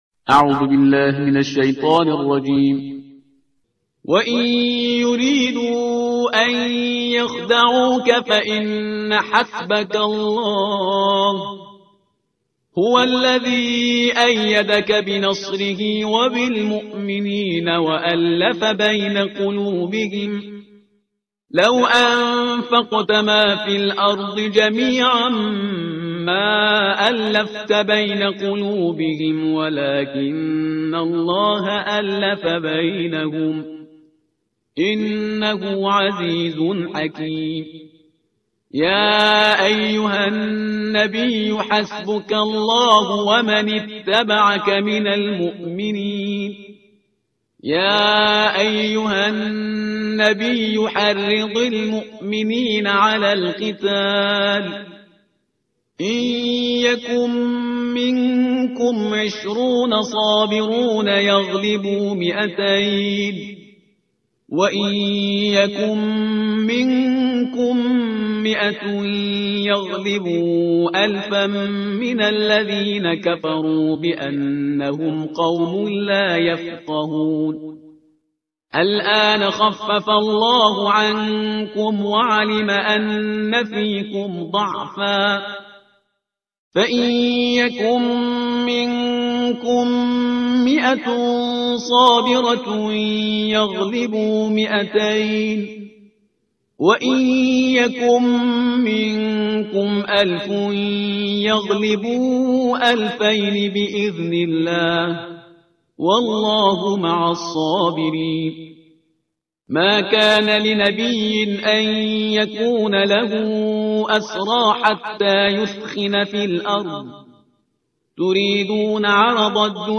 ترتیل صفحه 185 قرآن با صدای شهریار پرهیزگار